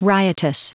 Prononciation du mot riotous en anglais (fichier audio)
Prononciation du mot : riotous